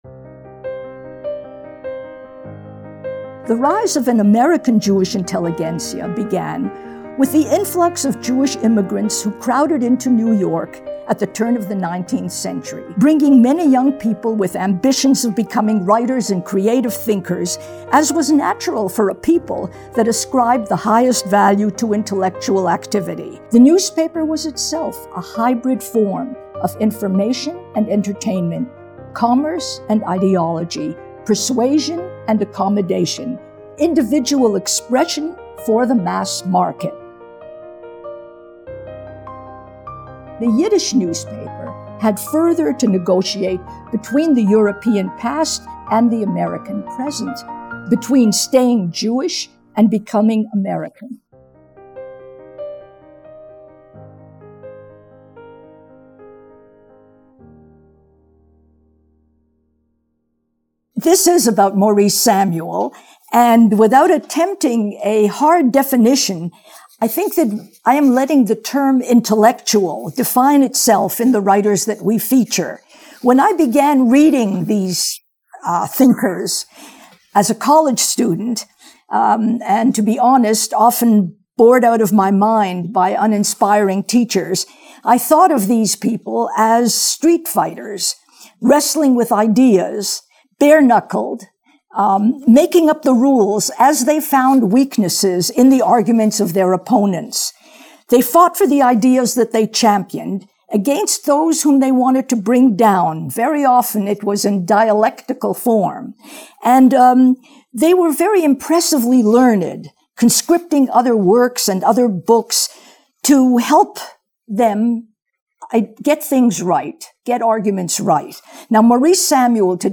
It was Maurice Samuel who first forged the path of the career Jewish public intellectual in America. In this lecture, Professor Wisse discusses his storied life and vast literary output.